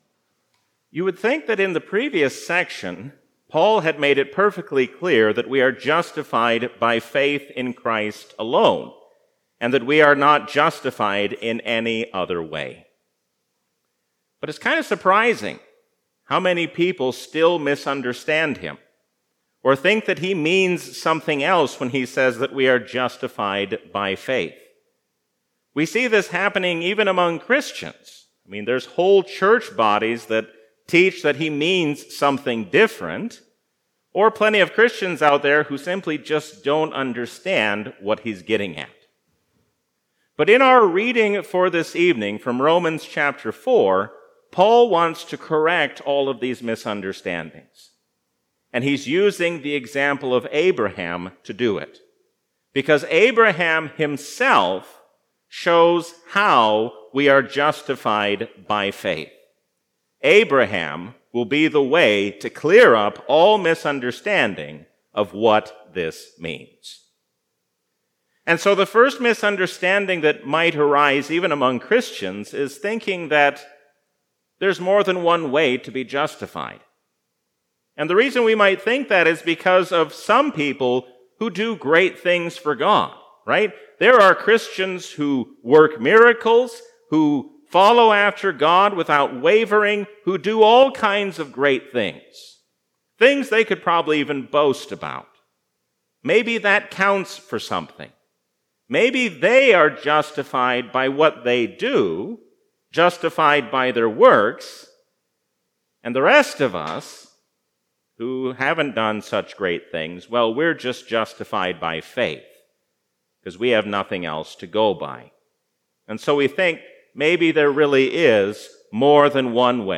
A sermon from the season "Easter 2022." Let us be Christians not only in our words, but also in what we do.